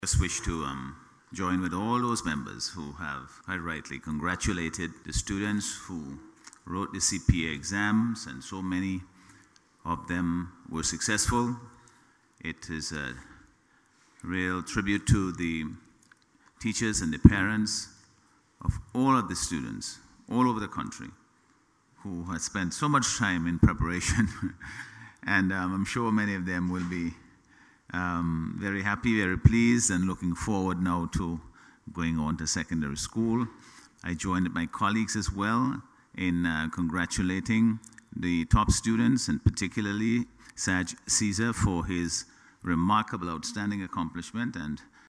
Minister of Health St Clair “Jimmy” Prince speaking in Parliament today also commended the boys for taking eight spots in the top 10.